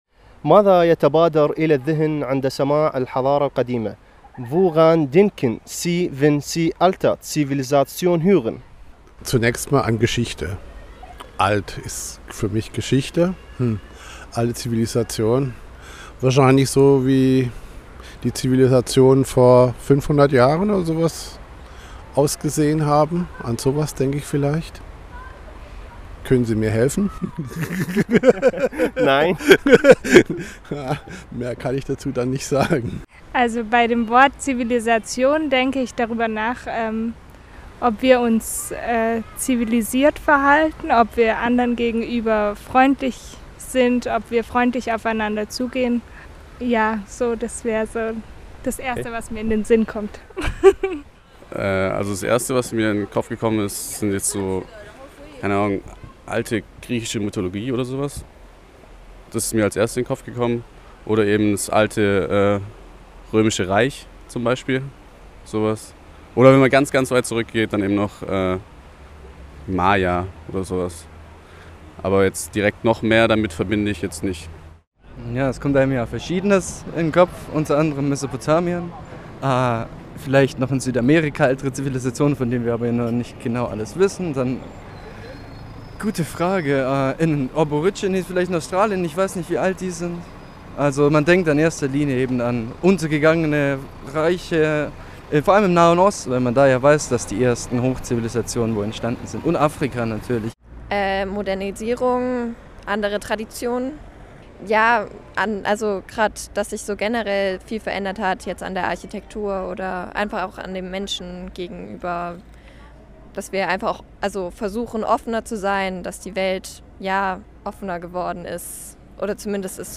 53925_umfrage_zivilisation_fertig.mp3